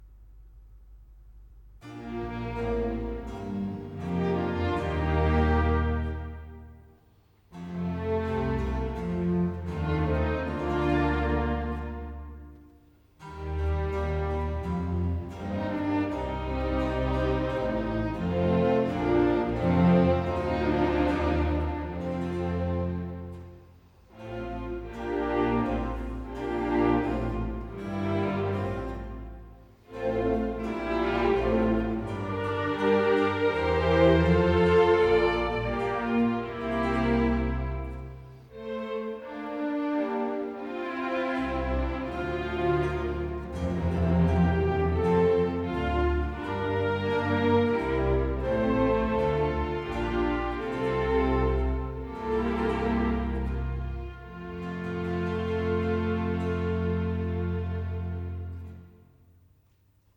B flat major - Largo